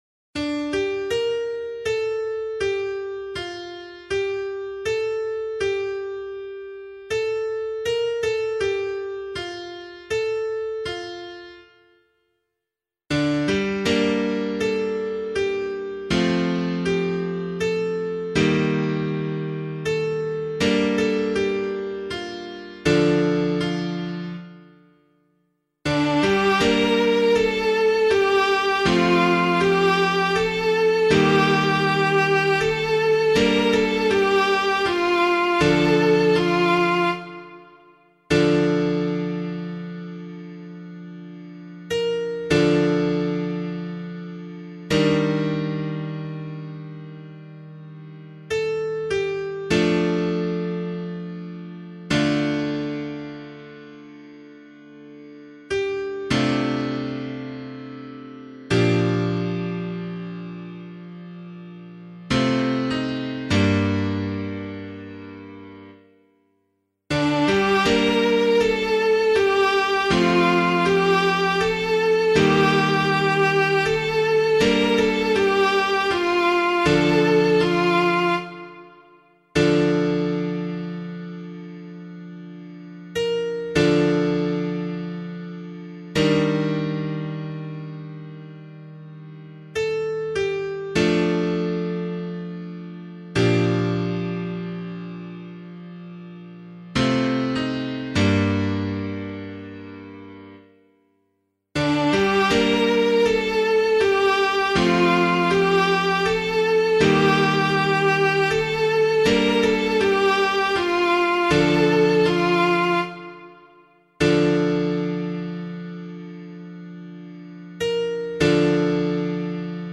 028 Easter 6 Psalm C [APC - LiturgyShare + Meinrad 3] - piano.mp3